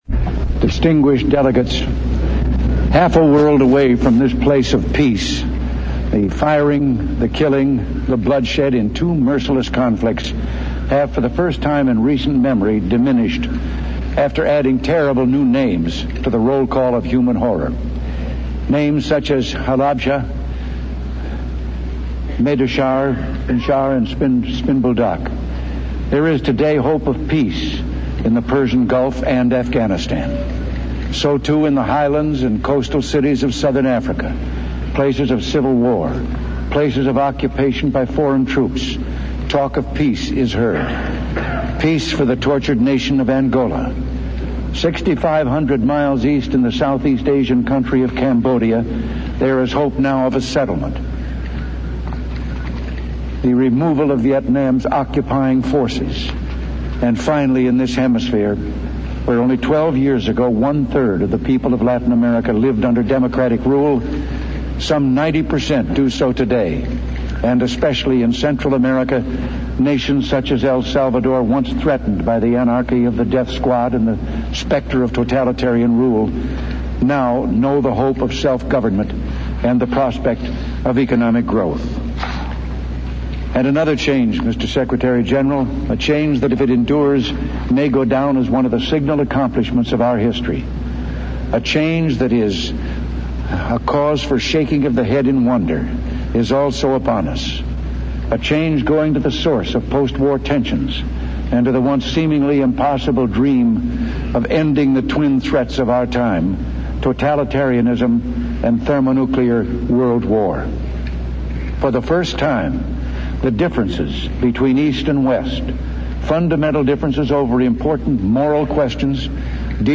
Ronald Reagan addressing the United Nations for the last time as U.S. President
Broadcast on CNN, September 26, 1988.